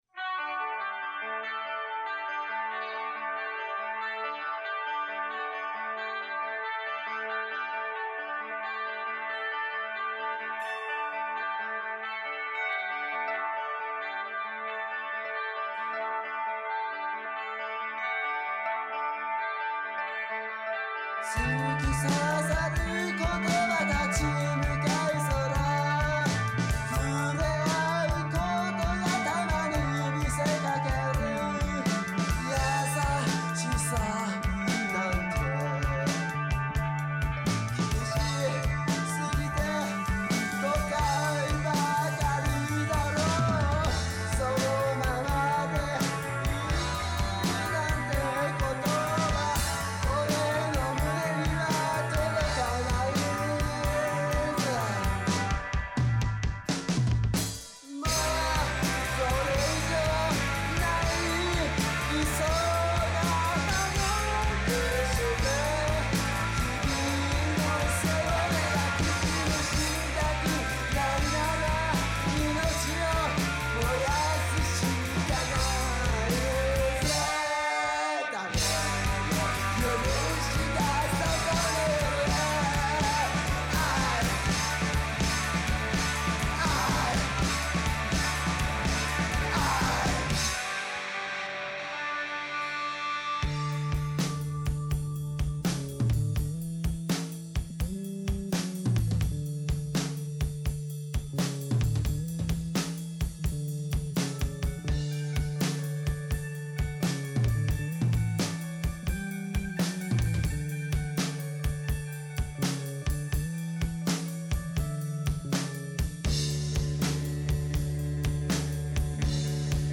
Vo＆G
難解なコード進行の現代曲に対抗し、1コードでオリジナリティあるメロディーを作ってやろうじゃないの…と燃えてます。